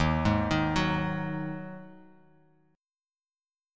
D#mbb5 Chord